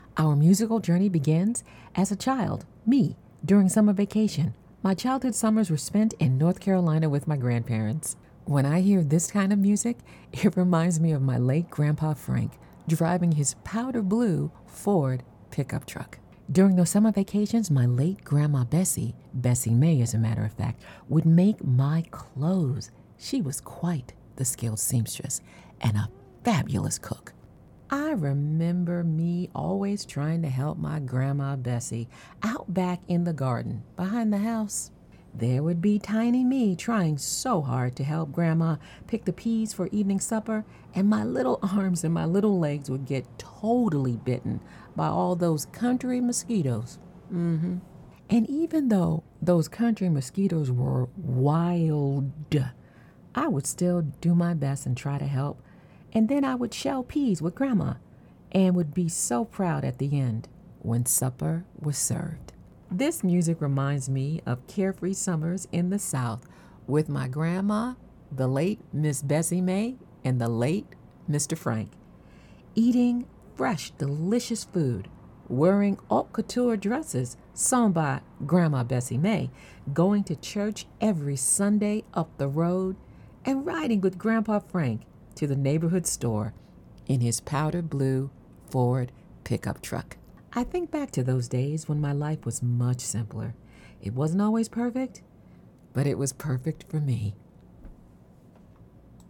Middle Aged